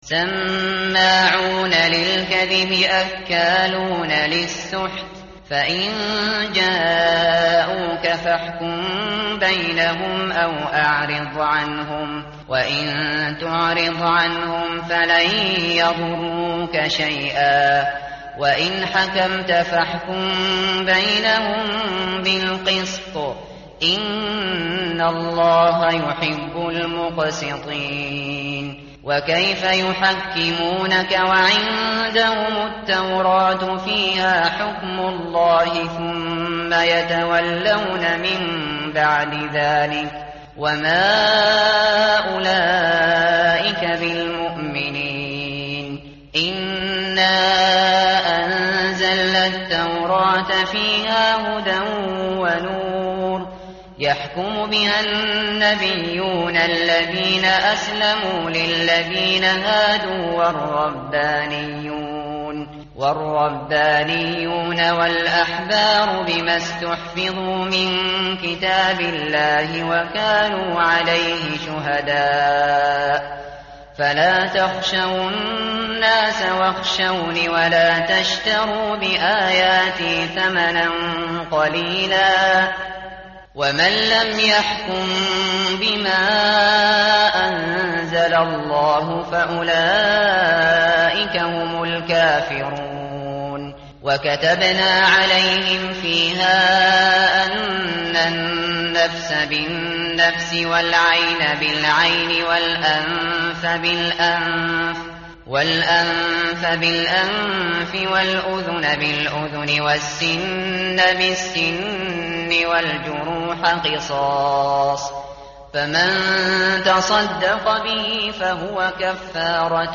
متن قرآن همراه باتلاوت قرآن و ترجمه
tartil_shateri_page_115.mp3